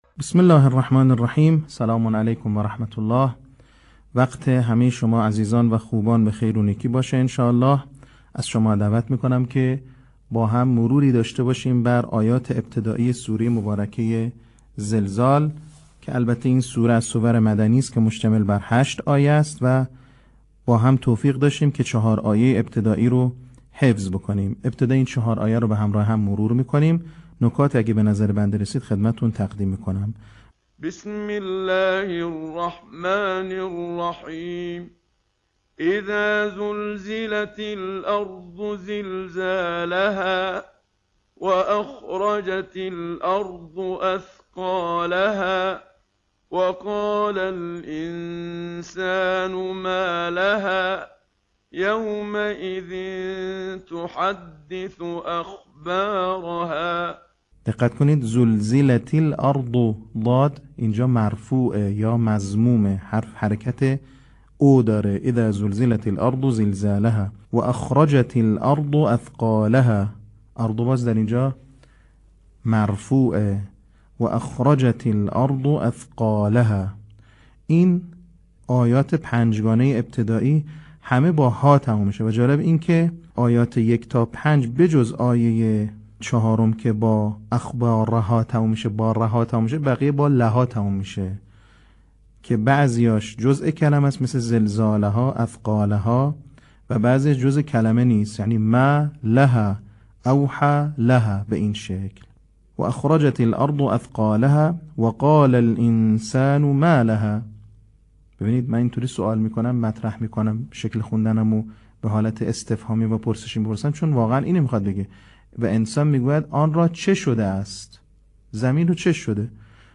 صوت | بخش دوم آموزش حفظ سوره زلزال